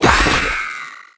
zling_dead.wav